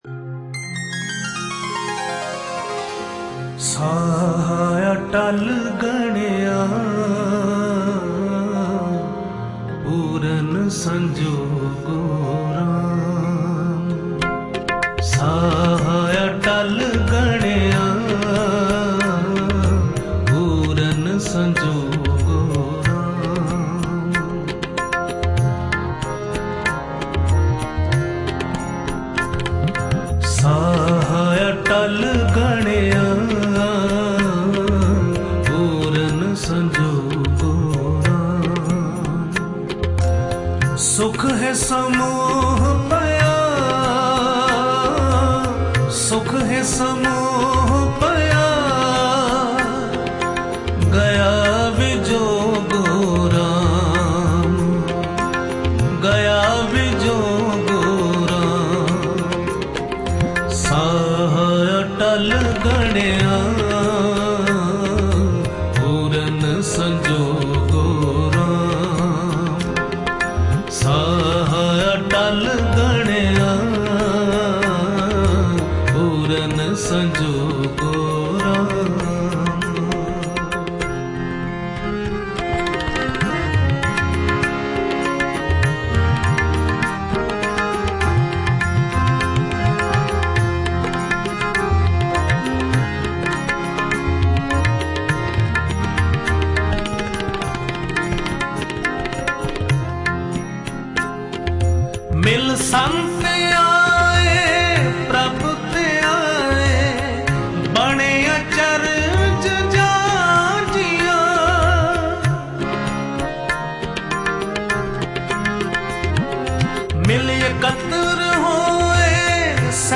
Hazoori Ragi